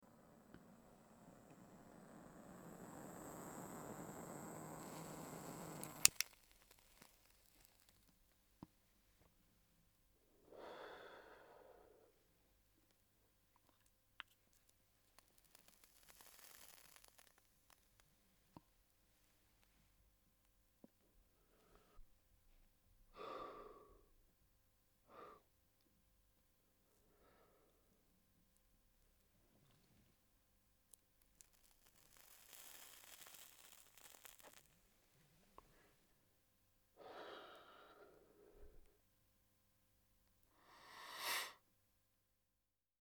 Звук курения самокрутки с мариванной